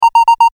ボタン・システム （87件）
文字送りdループ用.mp3